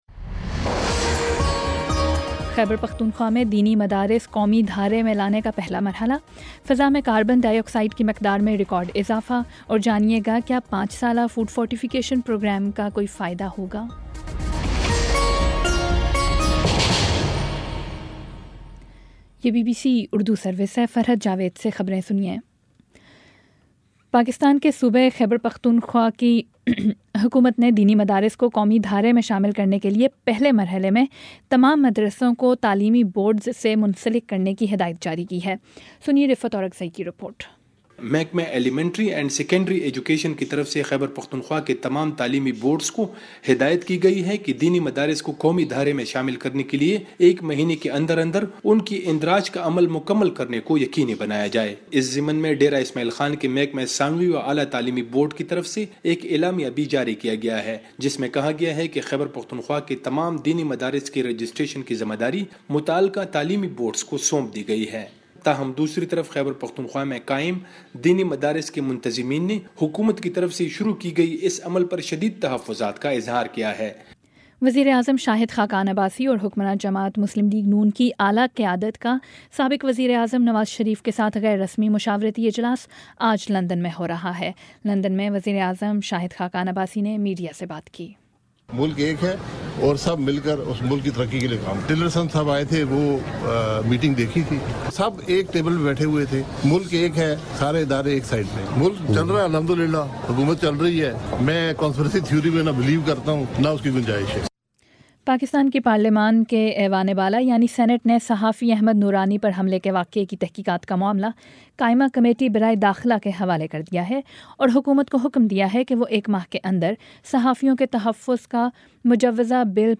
اکتوبر 30 : شام سات بجے کا نیوز بُلیٹن